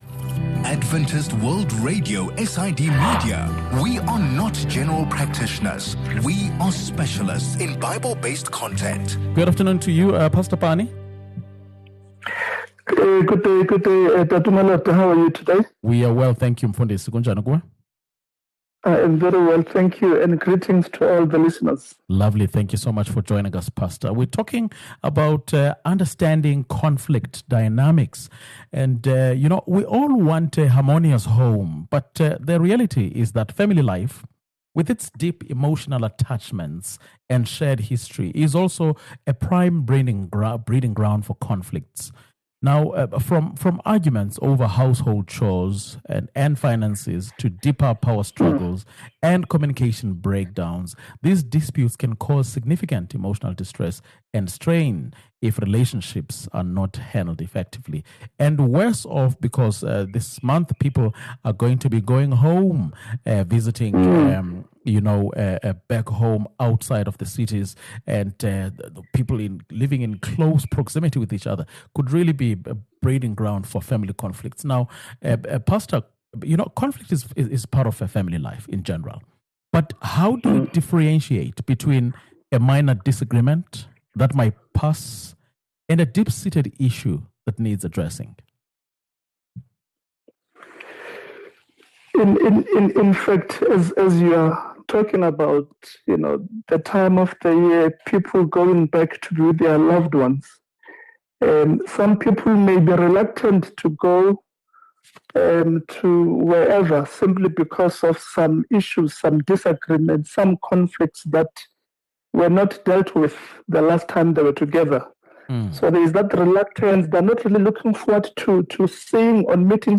In this conversation, we dive into the complex world of family dynamics to identify common conflict patterns and understand the ‘why’ and ‘how’ behind those recurring arguments.